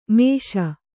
♪ mēṣa